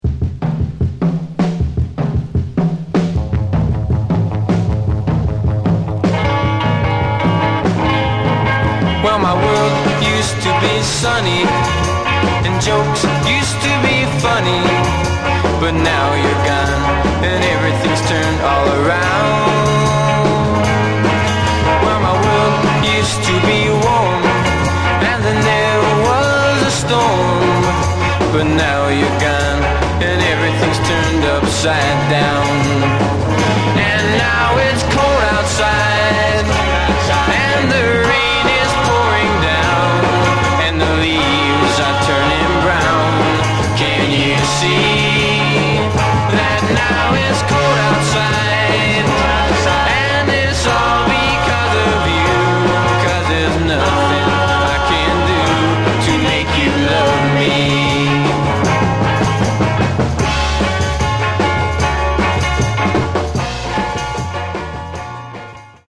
Genre: Garage/Psych